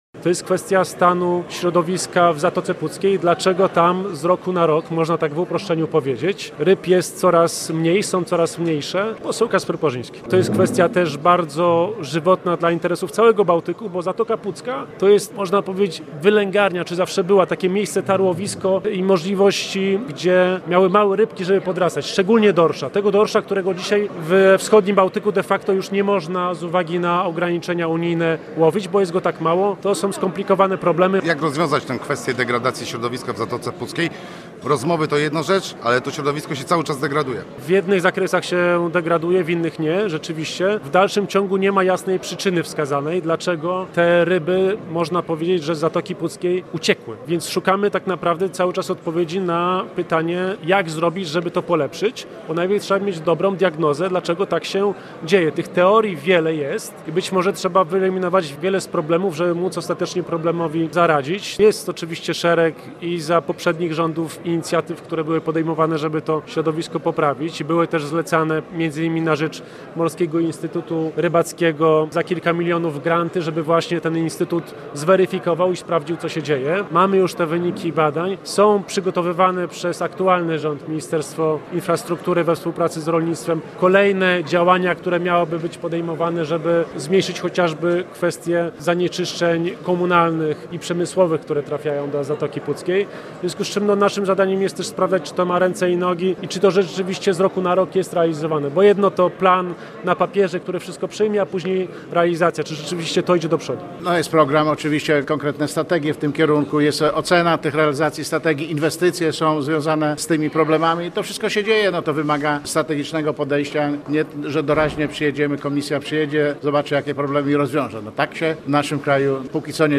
Posłuchaj materiału reportera: https